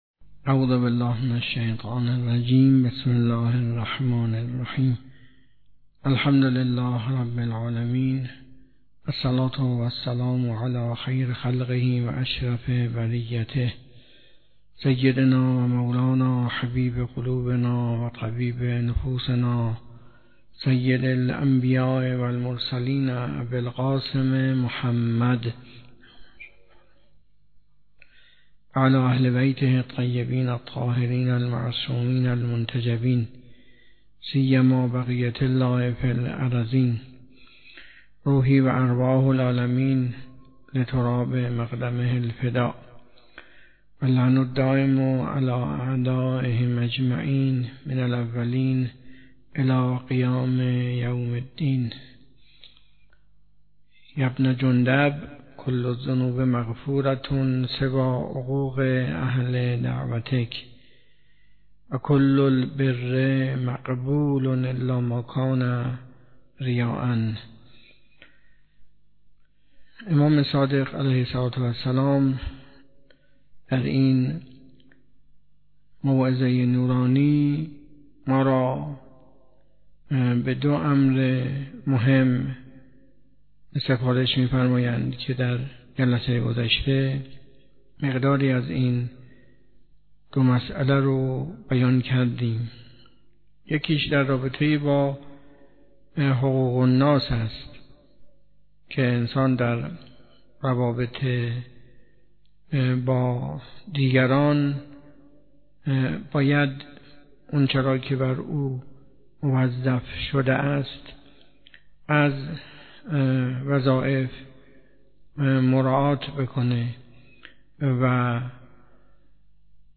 حوزه علمیه معیر تهران
درس اخلاق